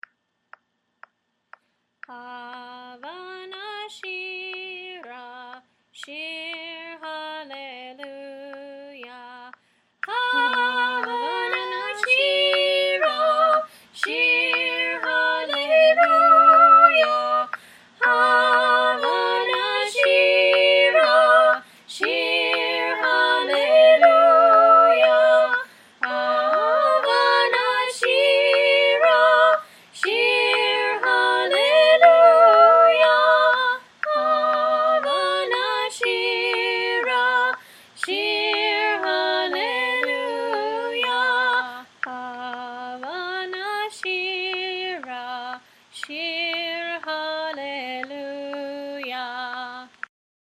And looky I can sing in 3-part harmony with myself!